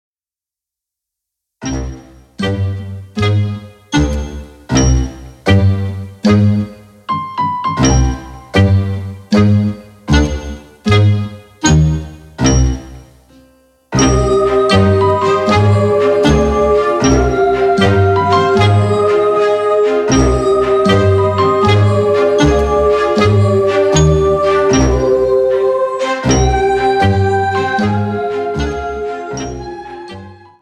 • Type : Instrumental
• Bpm : Adagio
• Genre : Ambient / Cinematic